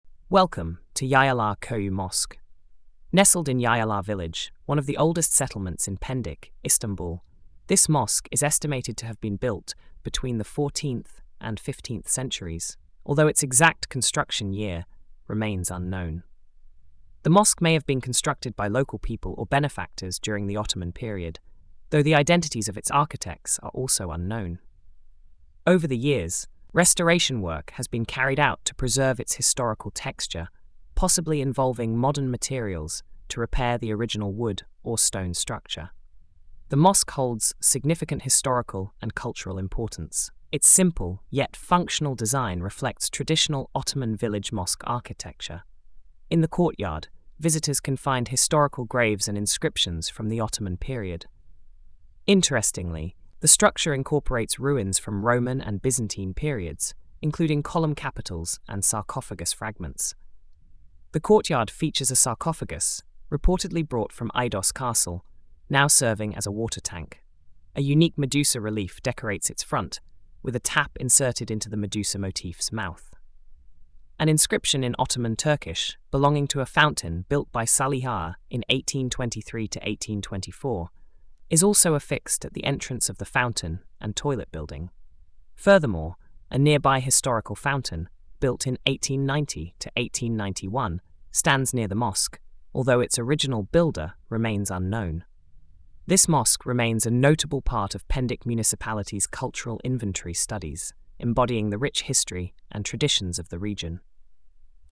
Audio Narration: